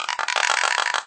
cup_rattle.ogg